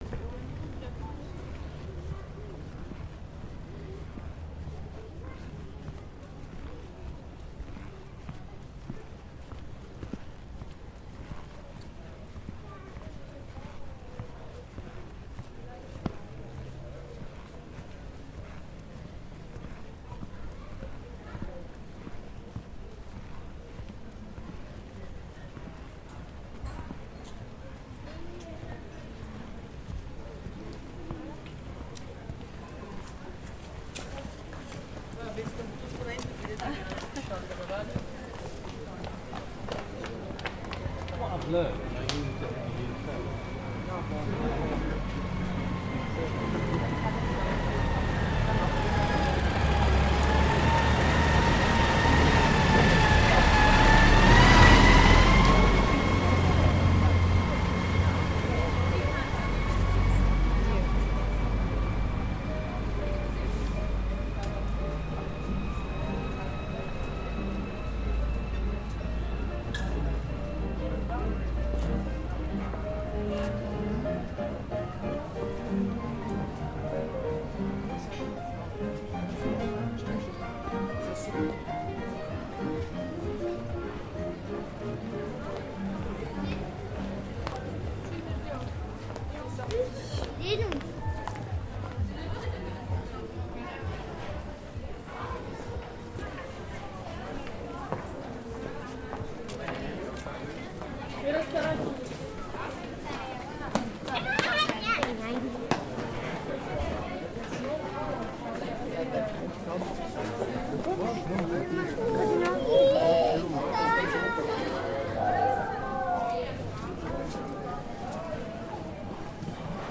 Street sounds in Montmartre, Paris, France Sound Effect — Free Download | Funny Sound Effects
Perfect for crowds, field-recording, France.